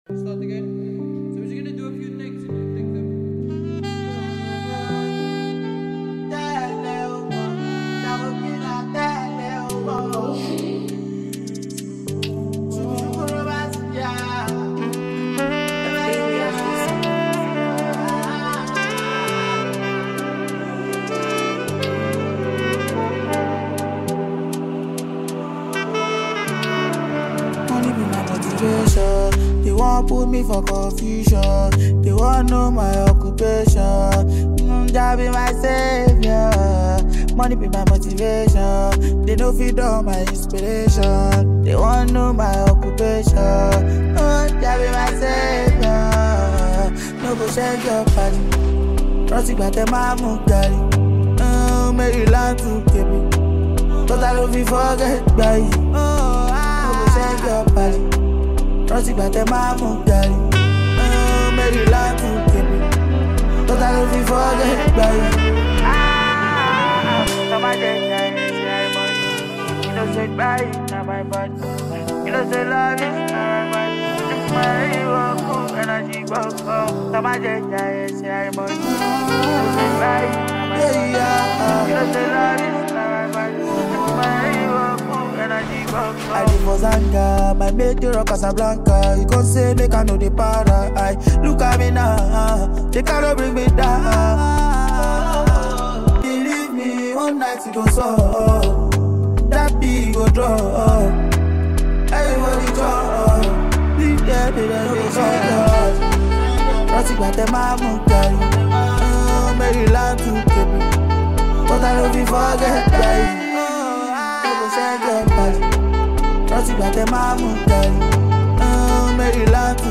new catchy tune